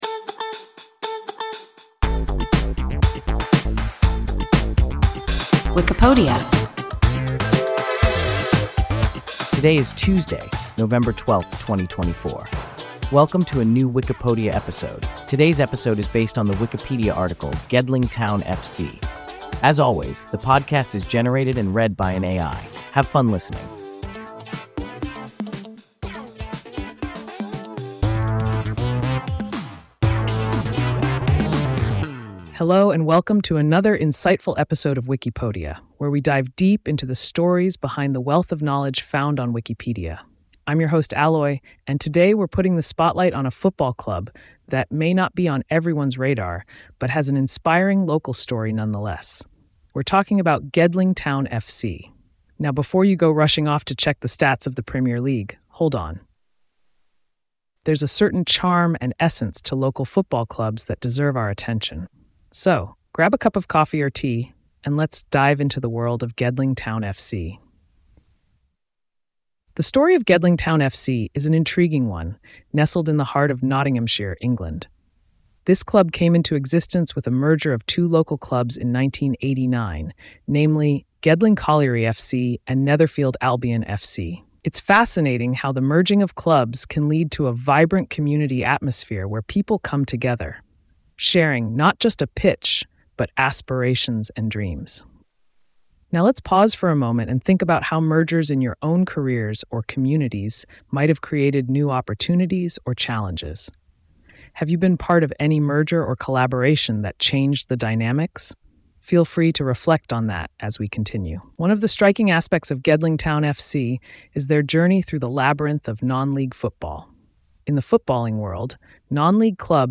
Gedling Town F.C. – WIKIPODIA – ein KI Podcast